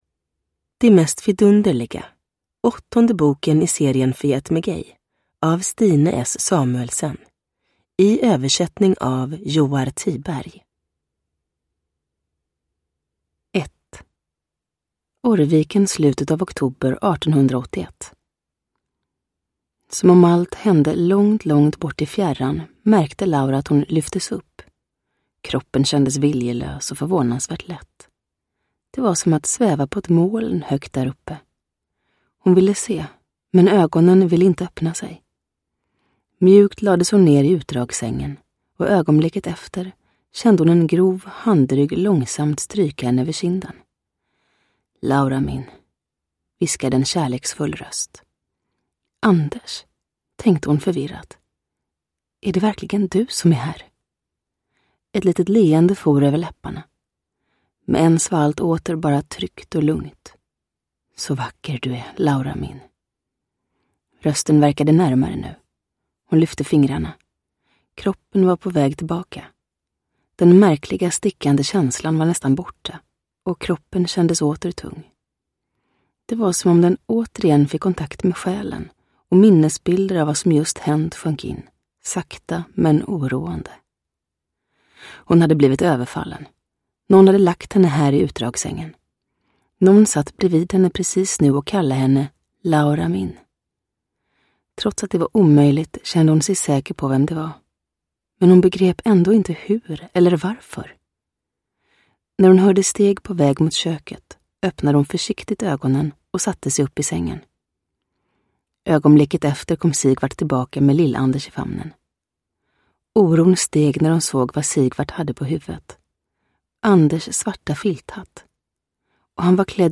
Det mest vidunderliga – Ljudbok – Laddas ner
Uppläsare: Julia Dufvenius